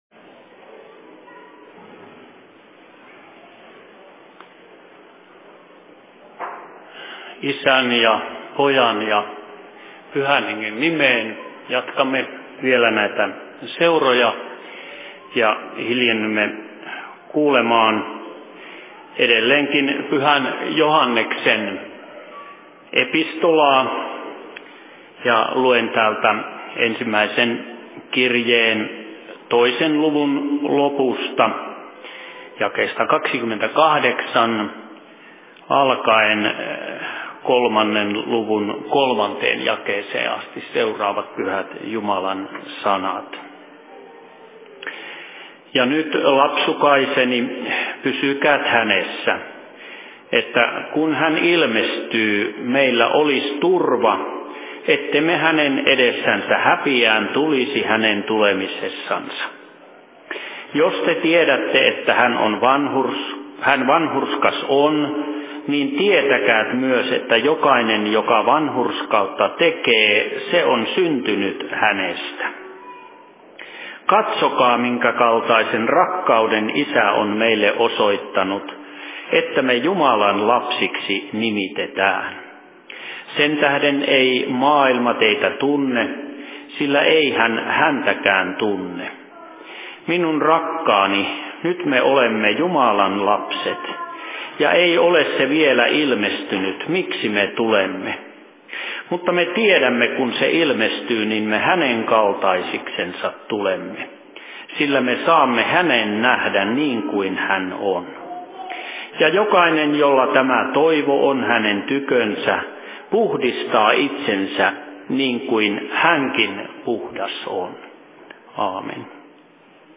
Seurapuhe 26.12.2013